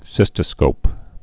(sĭstə-skōp)